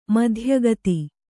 ♪ madhya gati